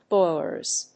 /ˈbɔɪlɝz(米国英語), ˈbɔɪlɜ:z(英国英語)/